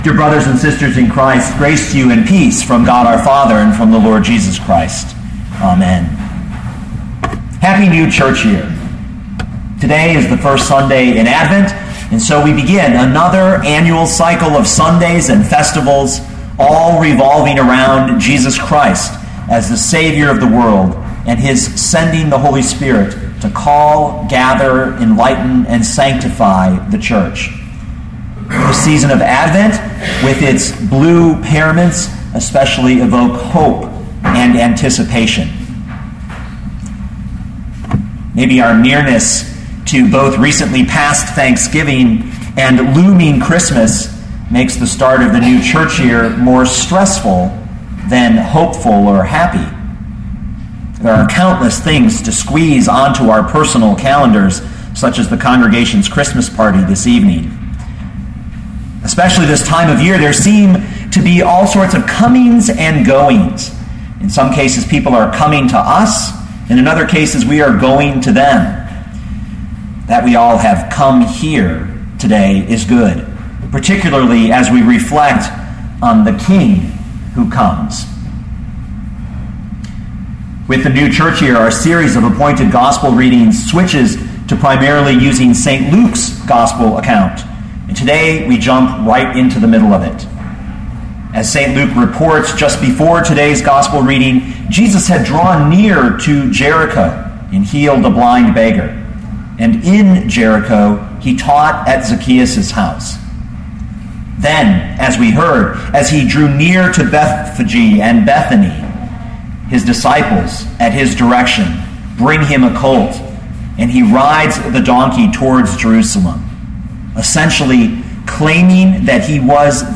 2012 Luke 19:28-40 Listen to the sermon with the player below, or, download the audio.